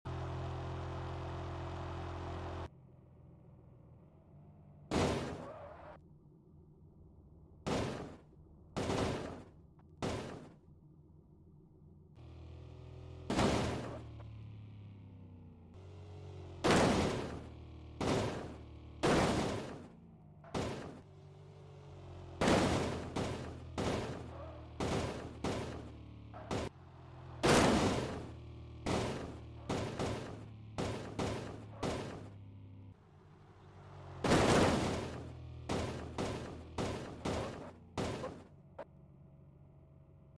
Crash Test Toyota Tacoma 2015 Sound Effects Free Download